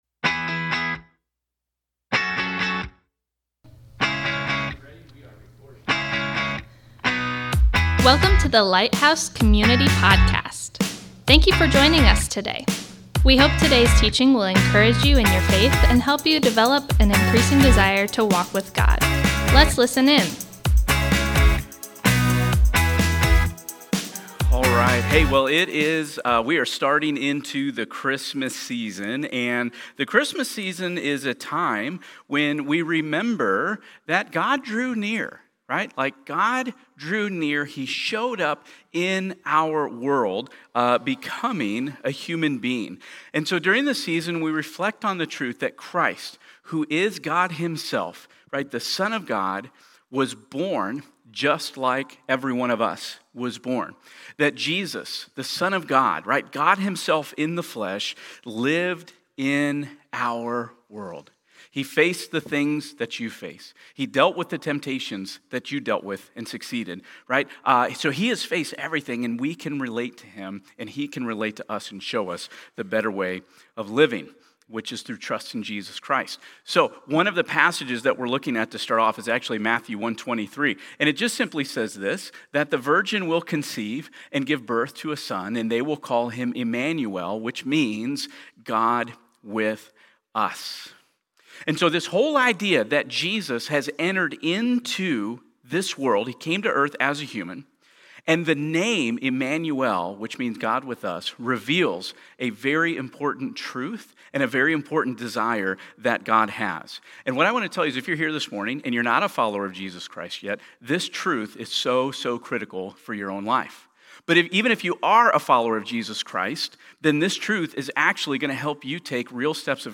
Thank you for joining us today as we come together to worship! This morning, we begin our Christmas series, “The Gift God Gave,” where we’ll unwrap the different aspects of God’s generosity and discover why an encounter with the living God changes everything. Today we’ll be looking at Matthew 1:23 as we unpack what it means to draw near to God.